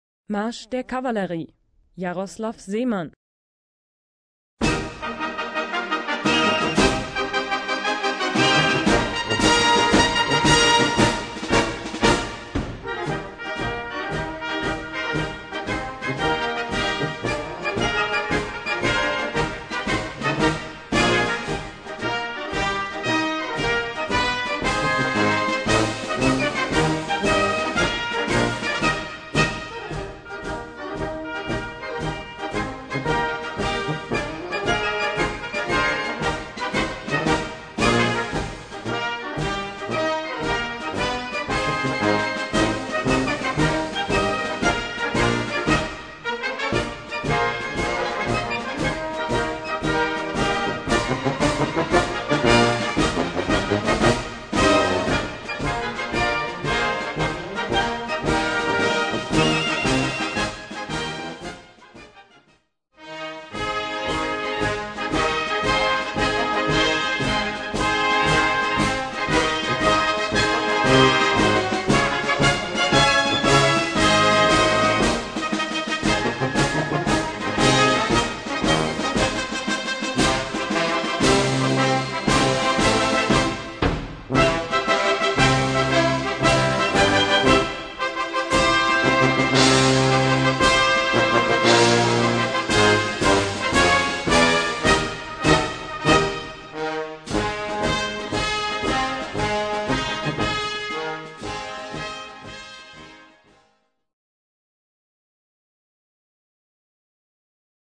Gattung: Marsch
A4 Besetzung: Blasorchester PDF